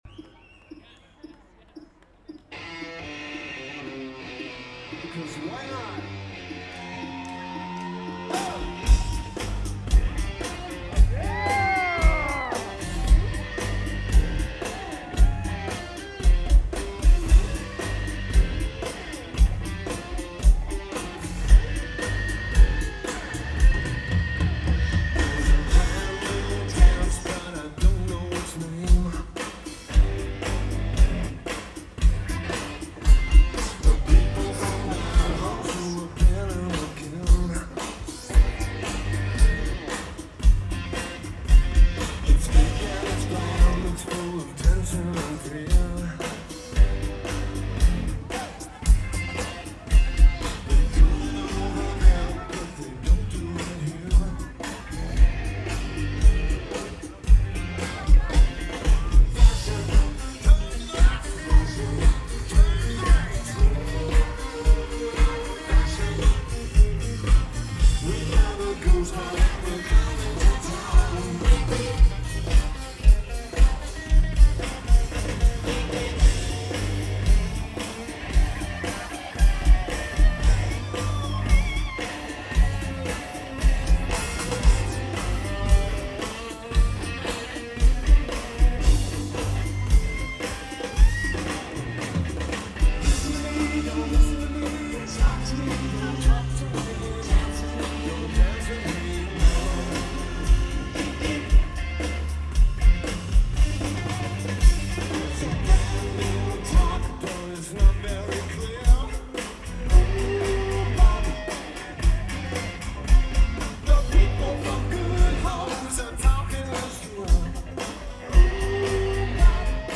Boston Calling
Lineage: Audio - AUD (CA14 Card + SP-SPSB-8-MKII + Zoom H1)